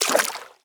footstep-water.ogg